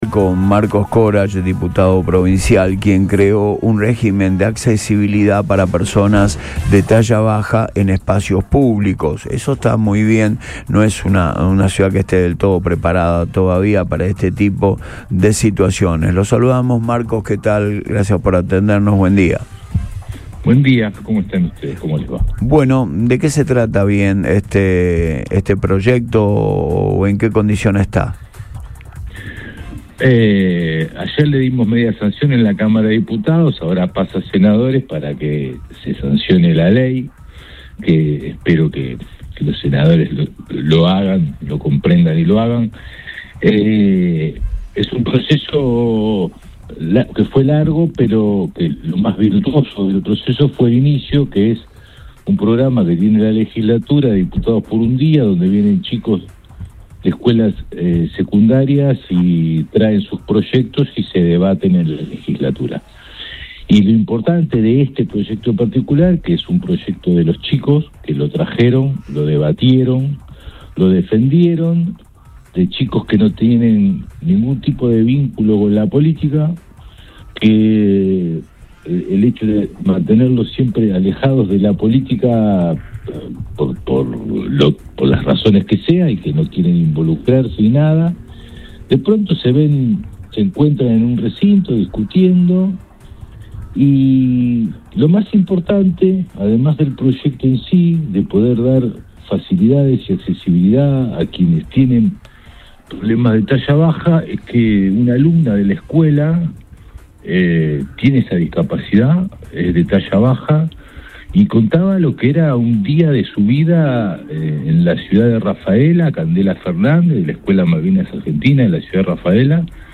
“Lo más valioso fue el origen del proyecto. Los chicos lo trajeron, lo defendieron y visibilizaron una problemática que no tiene tratamiento específico”, explicó el legislador en diálogo con el programa radial Antes de Todo, de Boing 97.3.